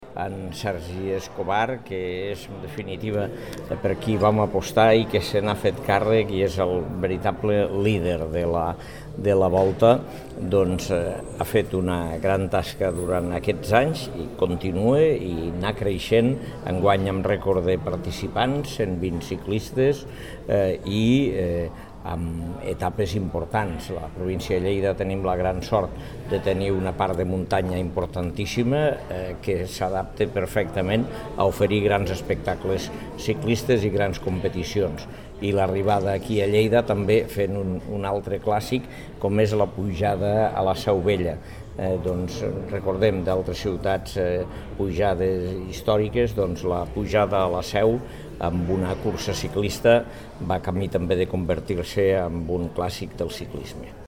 tall-de-veu-de-lalcalde-angel-ros-sobre-la-61a-volta-ciclista-internacional-de-lleida